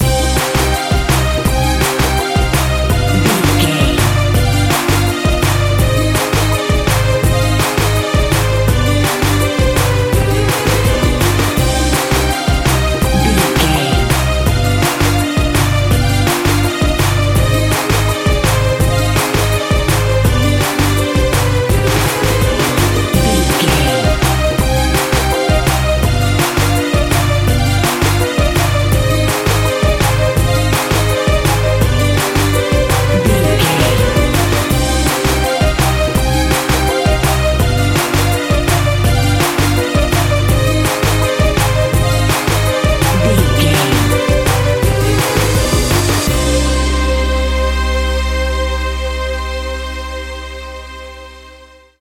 Aeolian/Minor
Fast
percussion
congas
bongos
djembe
marimba